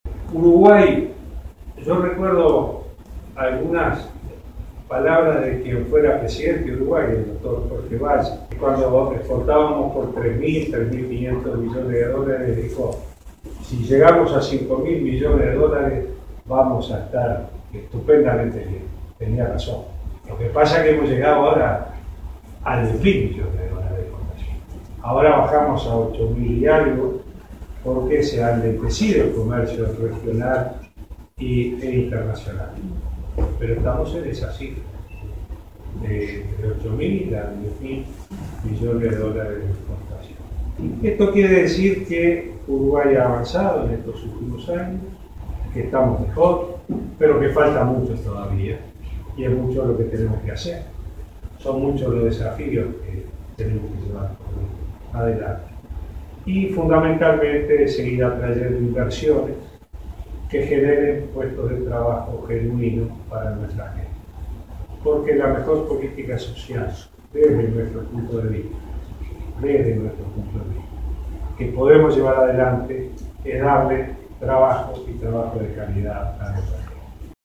El presidente Vázquez mantuvo un encuentro en Rusia con la colectividad uruguaya radicada en ese país y con empresarios. En ese marco, hizo un repaso de los avances de Uruguay en los últimos años y resaltó que el país ha llegado a 10.000 millones de dólares en exportaciones.